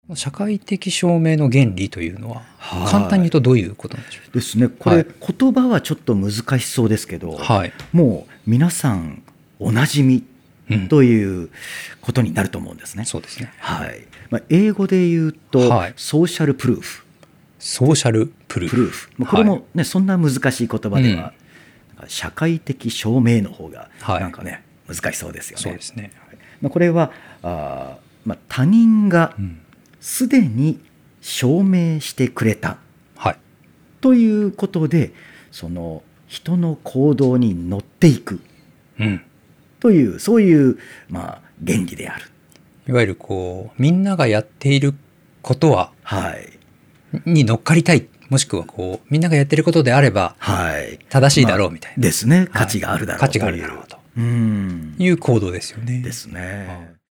この音声講座は上記の「カートに入れる」ボタンから個別購入することで聴くことができます。